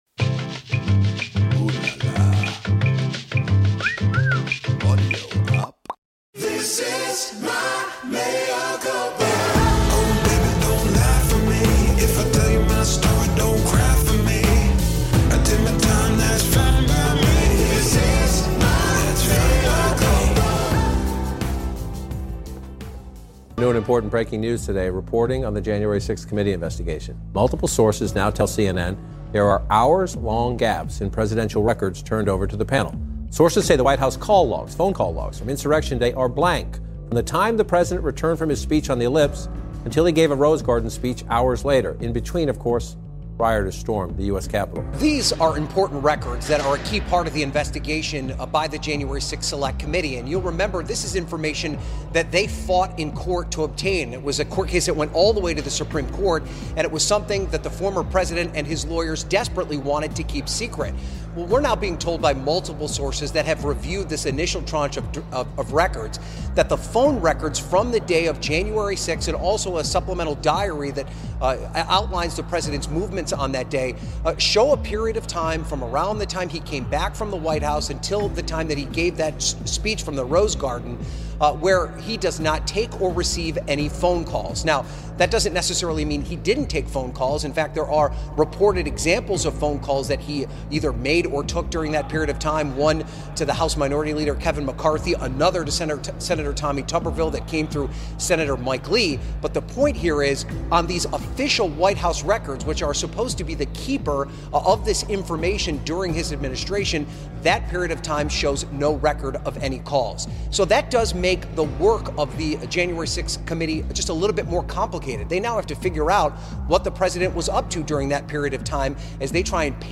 Trump Uncorks A Second Insurgency + A Conversation With Malcolm Nance
A deep dive into how Trump will use political violence in his quest to uncork a second insurgency. Former Naval Intelligence Officer and Security Expert Malcolm Nance who predicted January 6th joins Mea Culpa